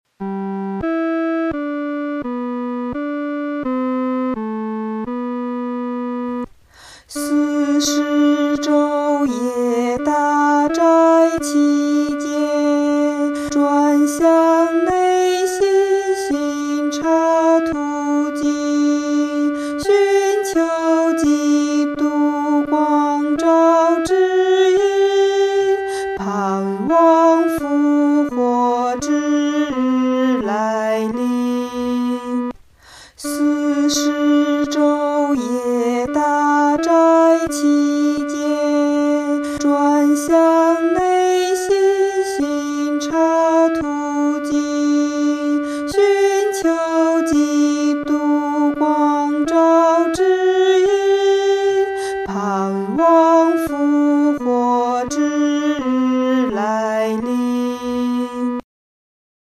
女低
这首诗歌充满着虔敬和恳切，我们在弹唱时的速度不宜太快。